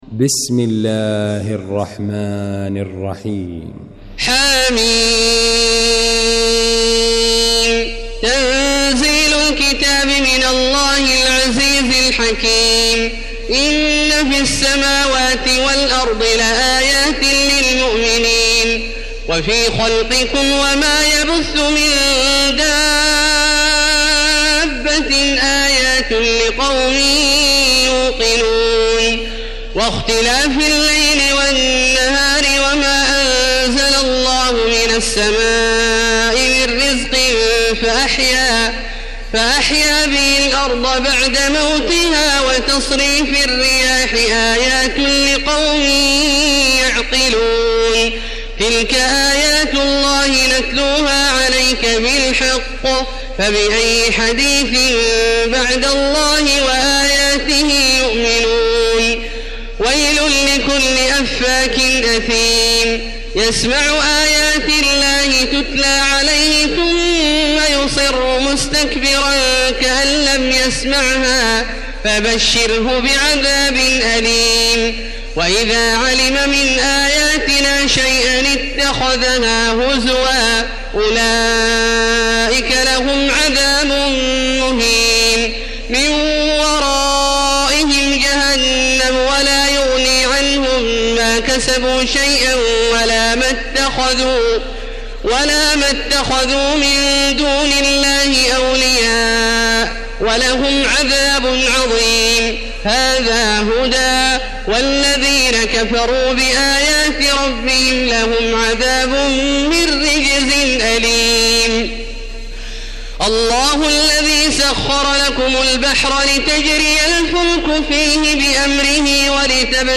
المكان: المسجد الحرام الشيخ: فضيلة الشيخ عبدالله الجهني فضيلة الشيخ عبدالله الجهني الجاثية